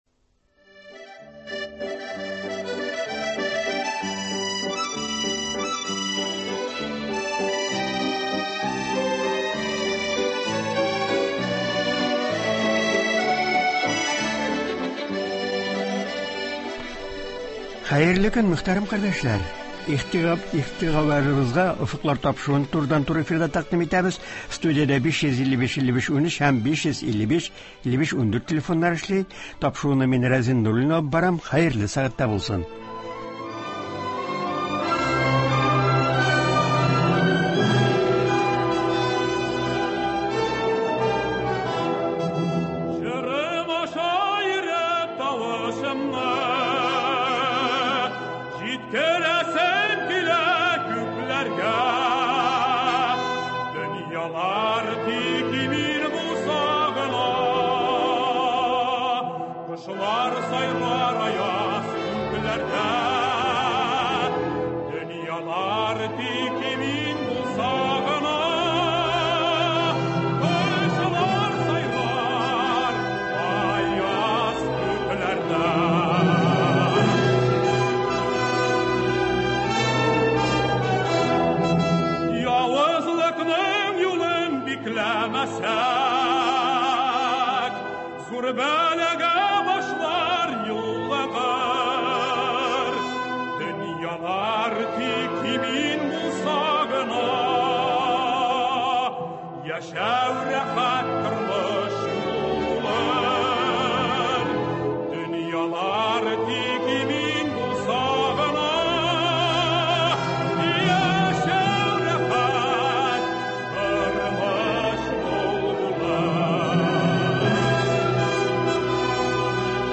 турыдан-туры эфирда сөйләячәк һәм тыңлаучылар сорауларына җавап бирәчәк.